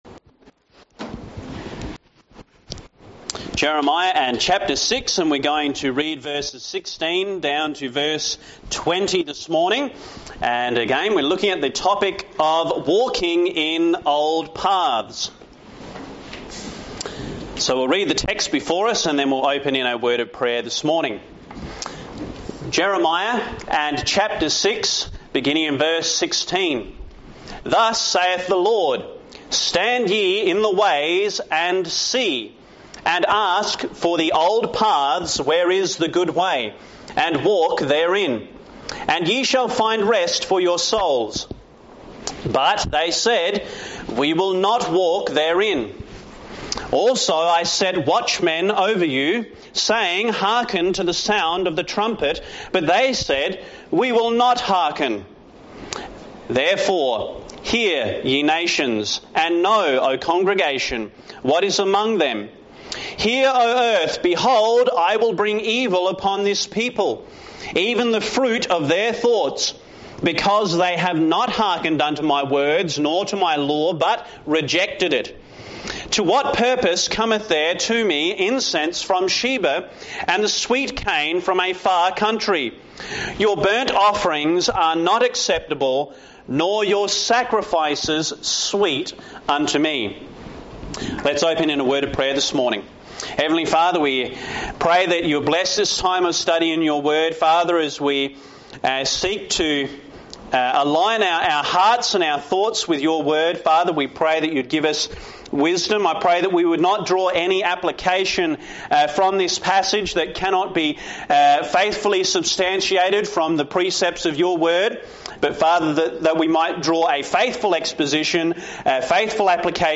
This message from Jeremiah 6:16-20 calls believers to return to God’s old paths, embrace biblical standards, and walk in obedience for true rest.